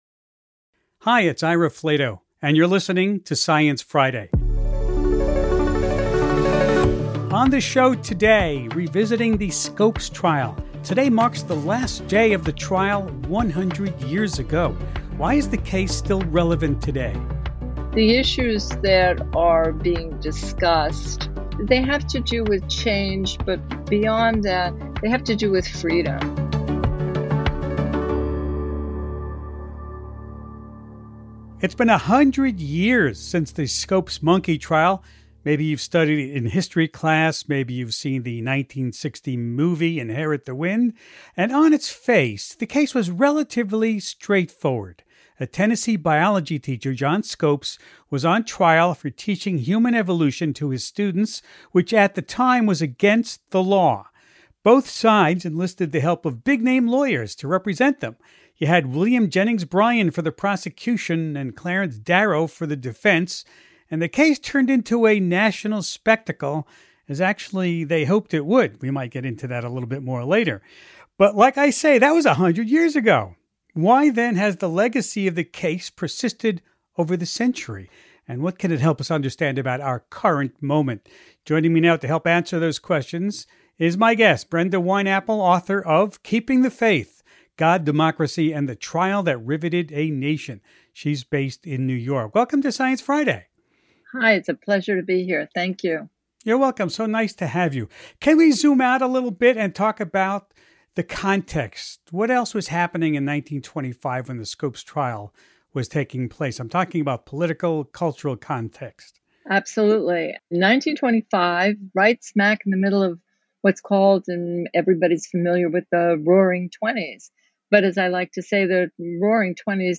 Host Ira Flatow talks with Brenda Wineapple, author of Keeping the Faith: God, Democracy, and the Trial that Riveted a Nation .